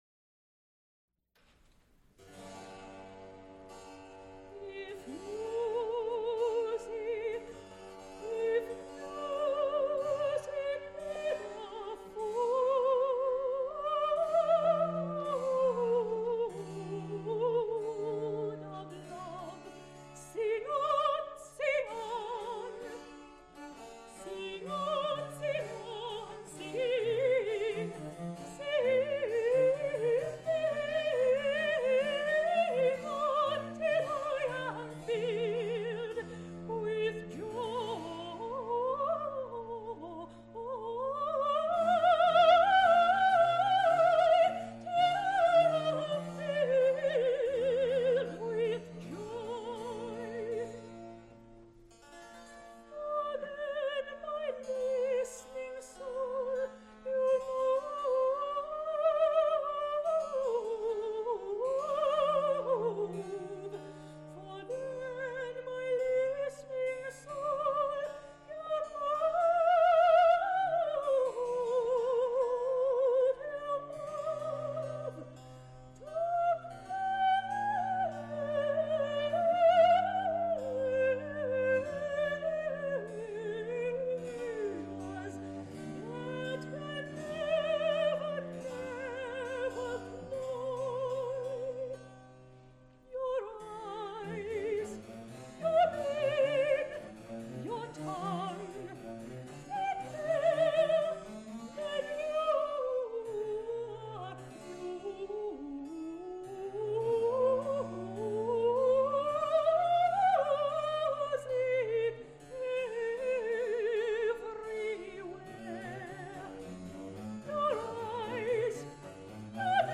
soprano
viola da gamba
recorder
harpsichord., Recorded live January 13, 1973, Frick Fine Arts Auditorium, University of Pittsburgh.
Extent 2 audiotape reels : analog, quarter track, 7 1/2 ips ; 12 in.
Viola da gamba and harpsichord music
Songs (High voice) with continuo